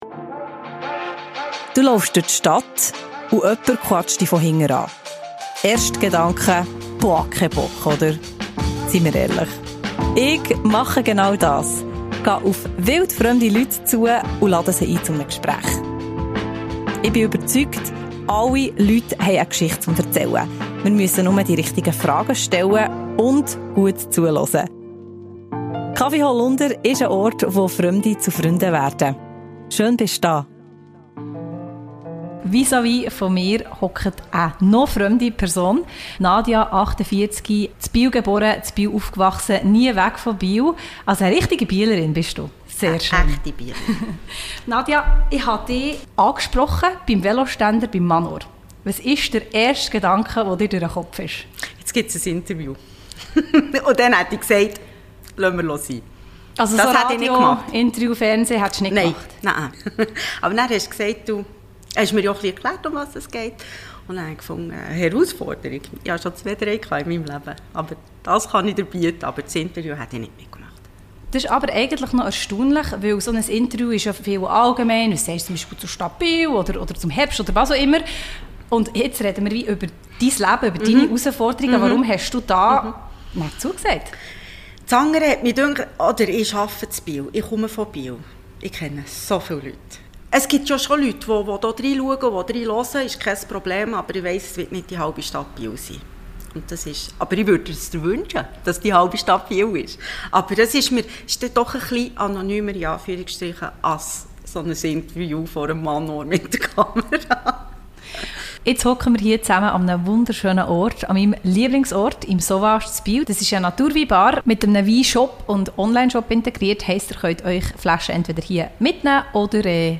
Beschreibung vor 2 Jahren Triggerwarnung: Im Gespräch reden wir über den Tod.